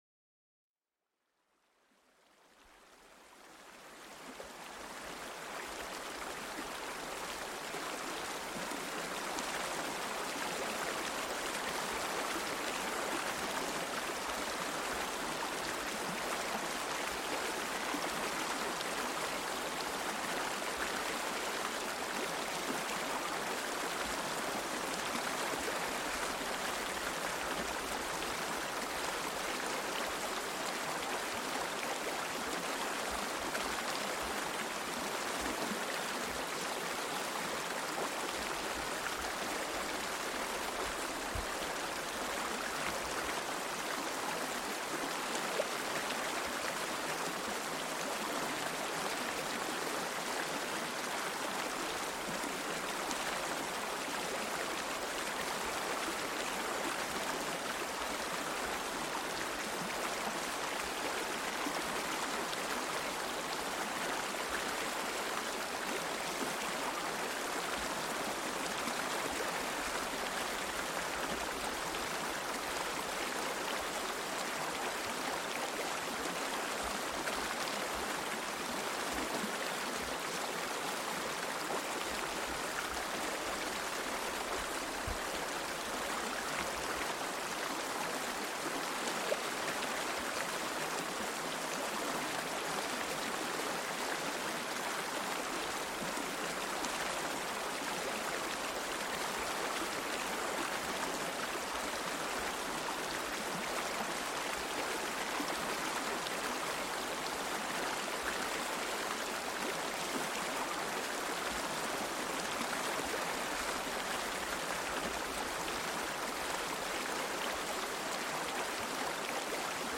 En este episodio, déjate llevar por el suave murmullo de un río relajante. El agua cristalina que fluye suavemente crea una atmósfera tranquila, perfecta para relajarse y recargar energías.